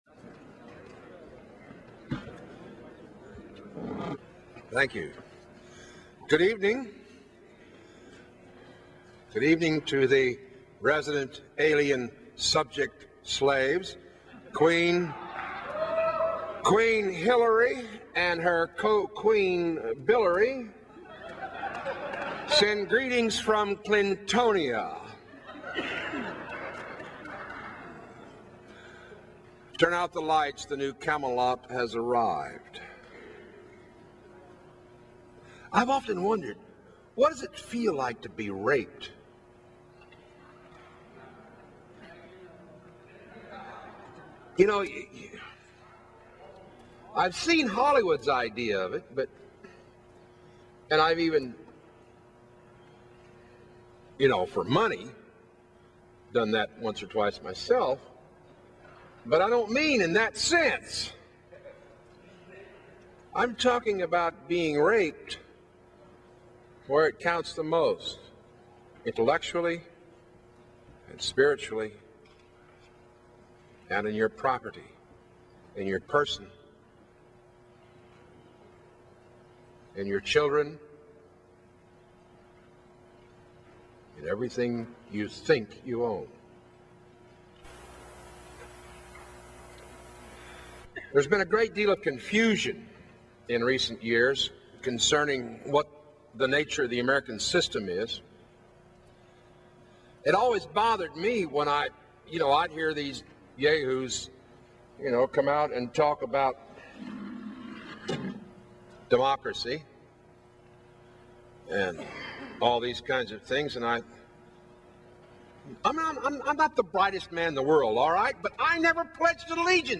The speech is from 1993 ...but, it is inspirational even today